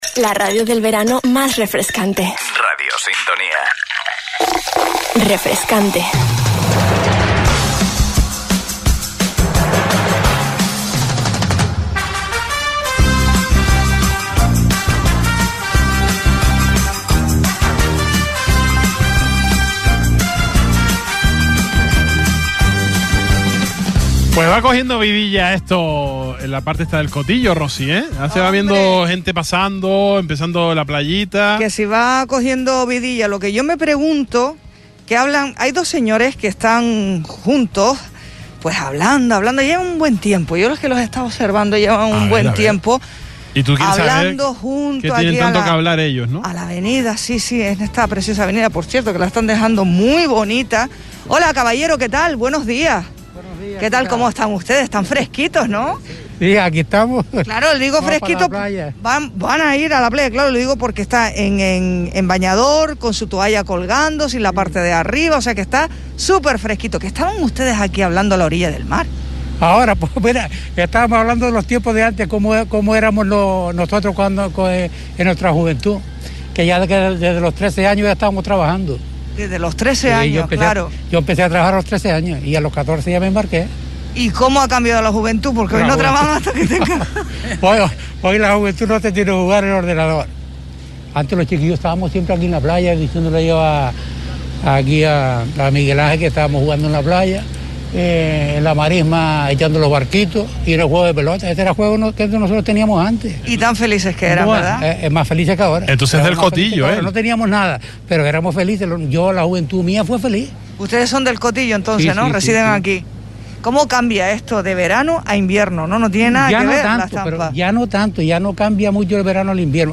El Salpicón, directo desde El Cotillo, paseo por el pueblo - 20.08.21 - Radio Sintonía